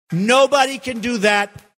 Tags: ASA Sports announcing